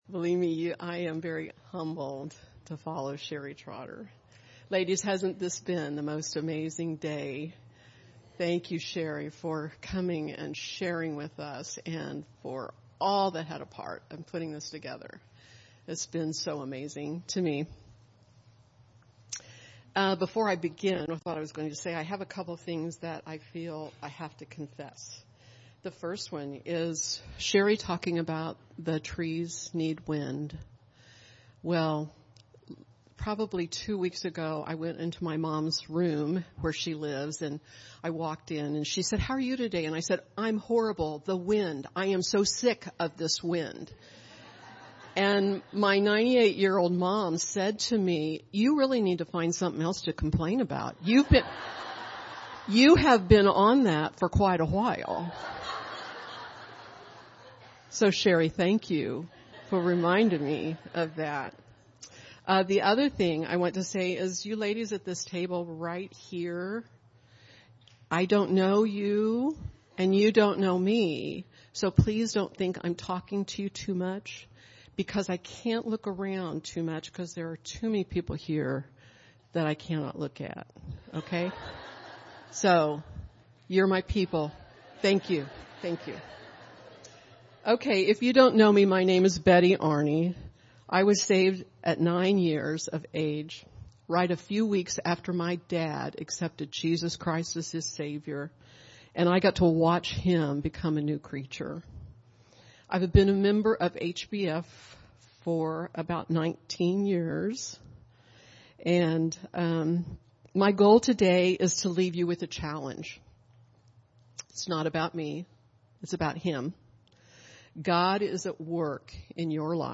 Woman's Conference | Heartland Baptist Fellowship
Testimony & Aged Women Panel